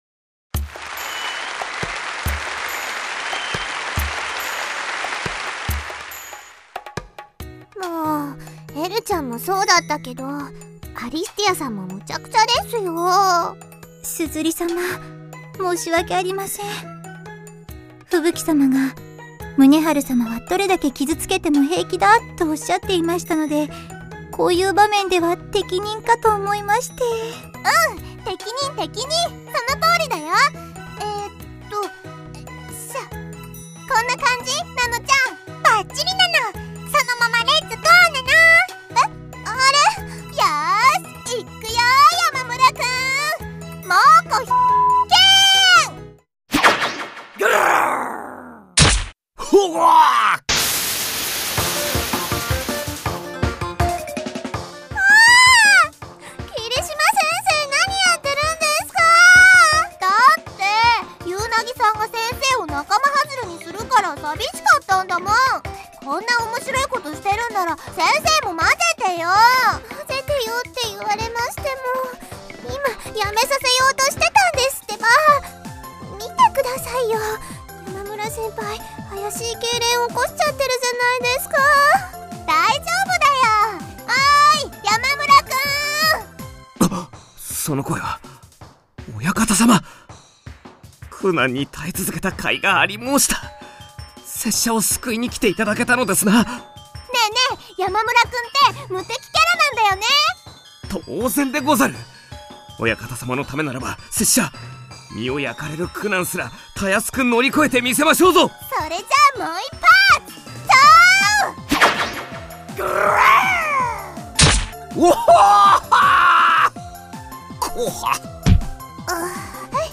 WEBドラマ 第6回を公開！！